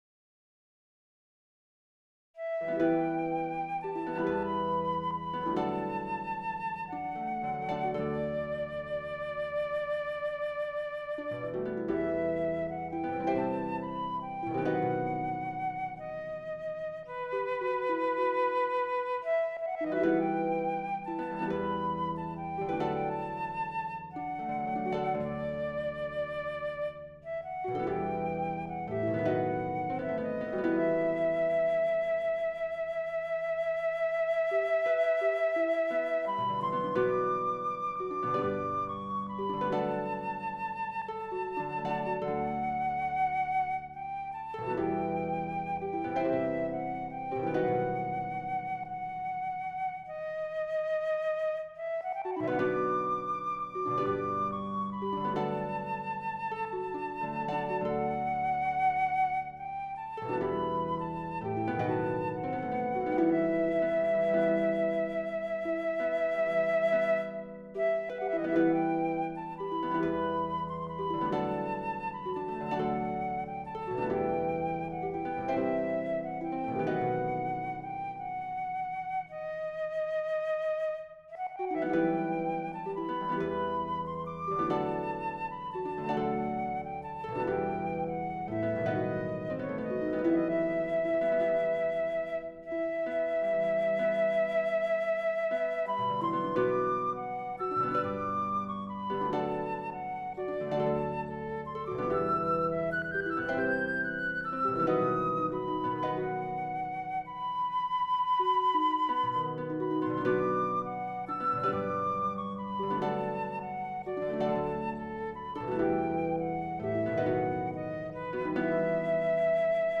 Voicing/Instrumentation: SATB , Guitar